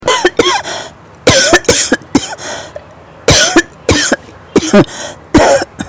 干咳2.mp3